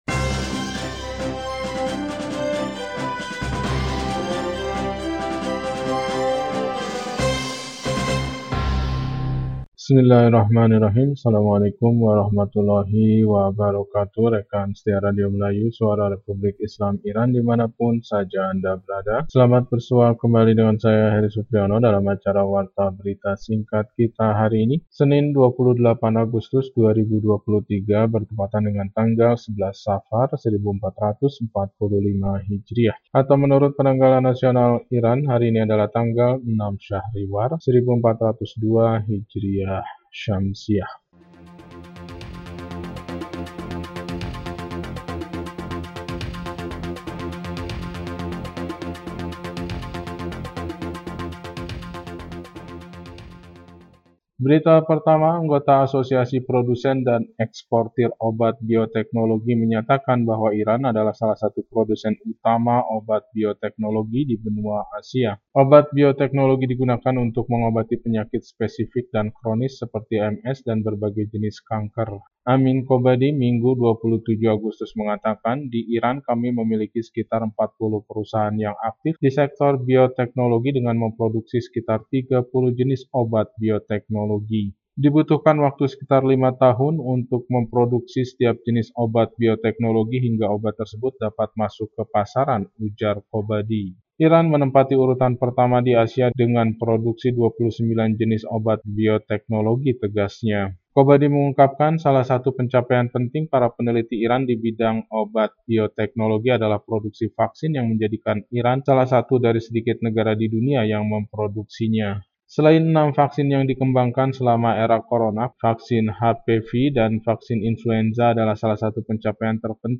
Warta Berita 28 Agustus 2023